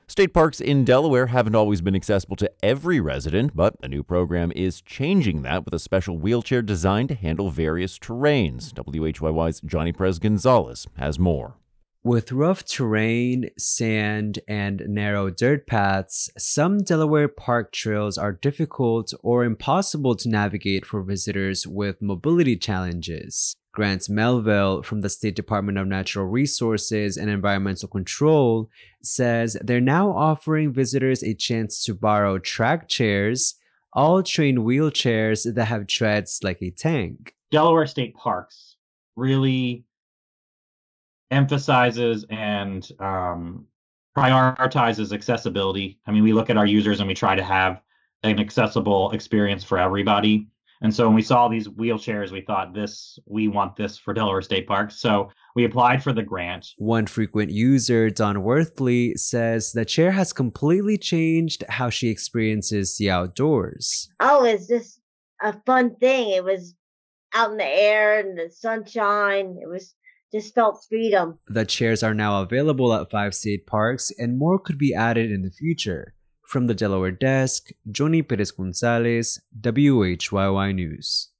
NewsWorks Tonight was a daily radio show and podcast that ran from 2011-2018.